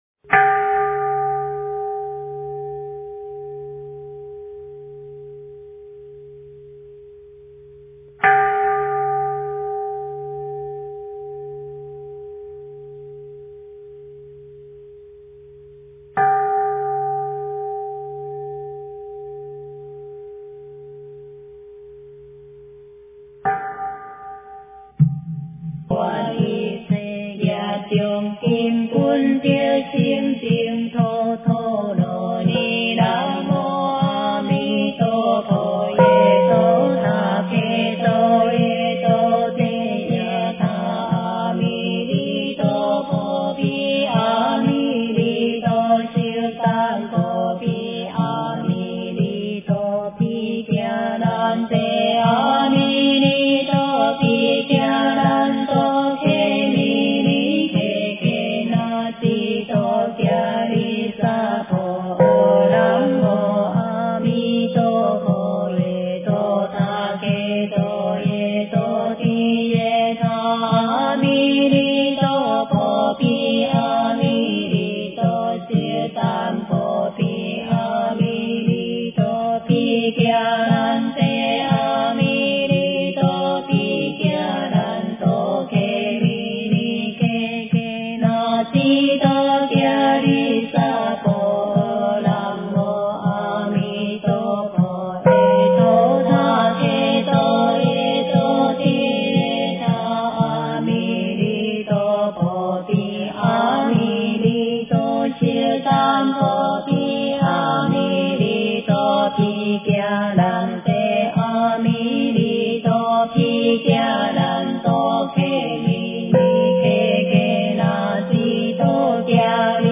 经忏
佛音 经忏 佛教音乐 返回列表 上一篇： 药师经--圆光佛学院众法师 下一篇： 初一十五法会--中国佛学院法师 相关文章 宝鼎祝福香赞--如是我闻 宝鼎祝福香赞--如是我闻...